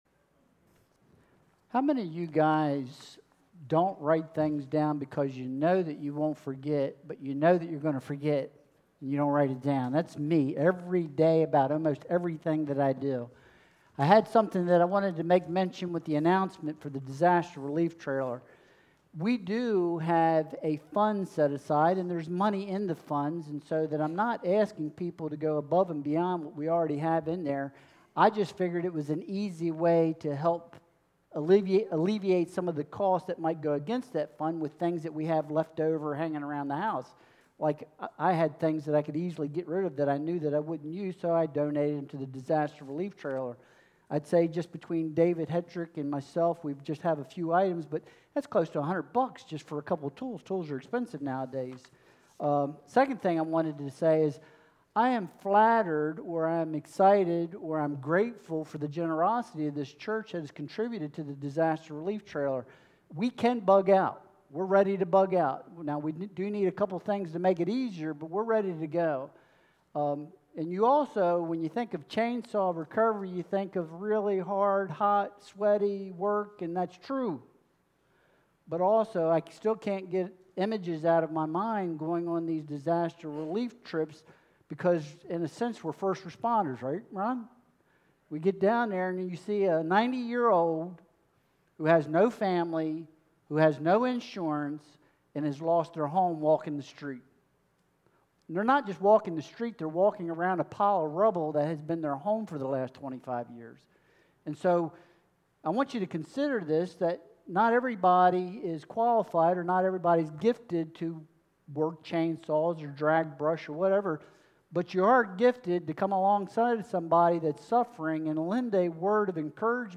Leviticus 19.13-18 Service Type: Sunday Worship Service Download Files Bulletin « Does God Approve?